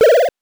8 bits Elements
powerup_42.wav